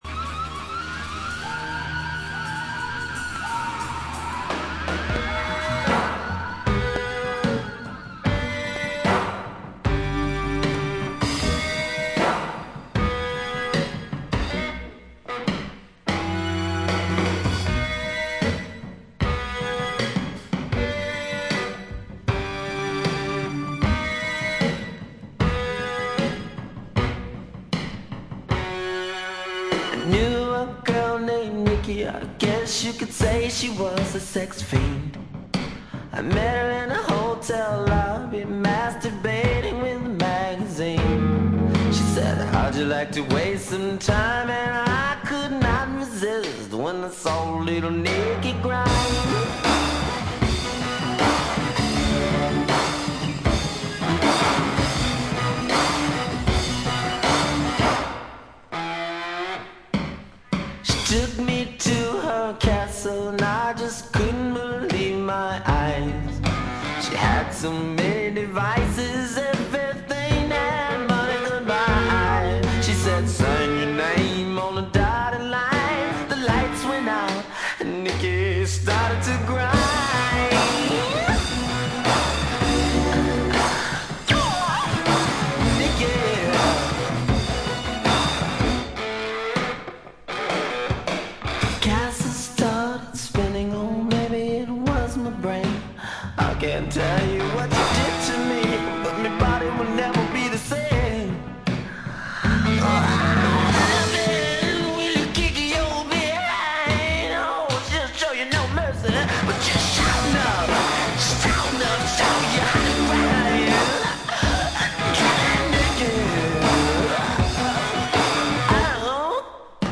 A5冒頭プツ音3回、気づいたのはこれぐらいでほとんどノイズ感無し。
試聴曲は現品からの取り込み音源です。